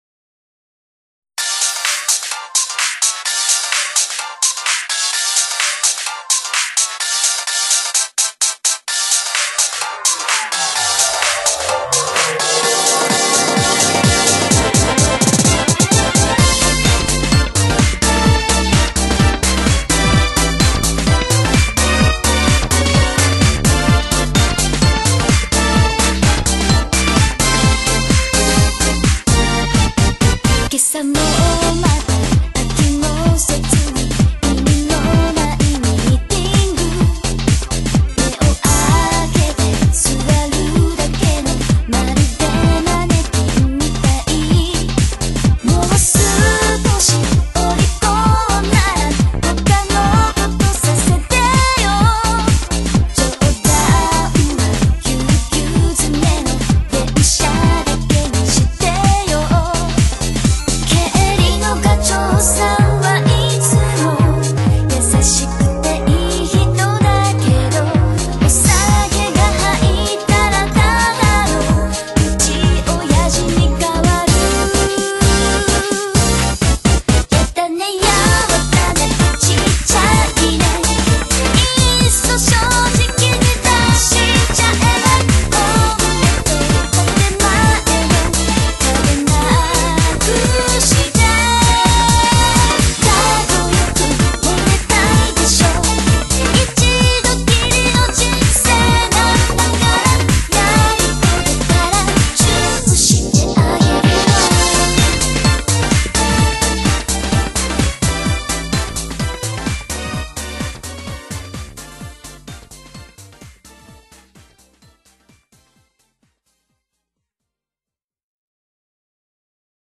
BPM128-128
Audio QualityPerfect (Low Quality)
A bubbly house track